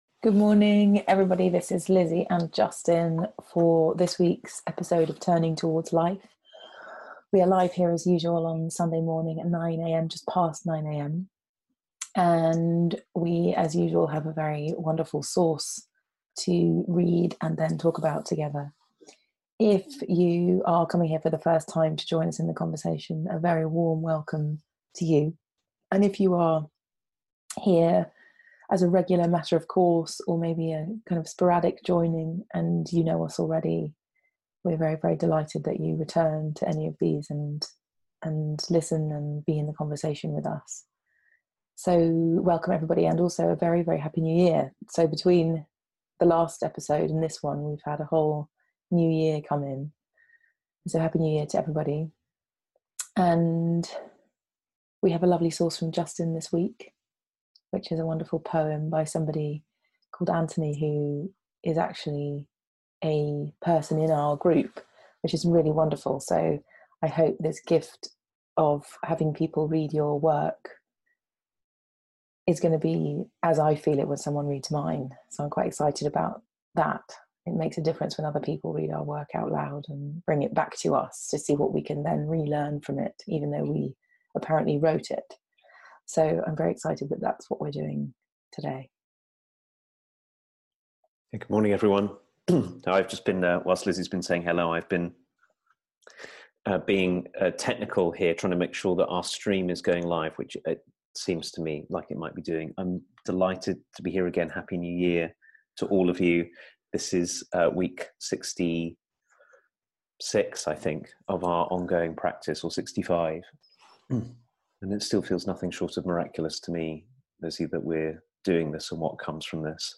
weekly live 30 minute conversation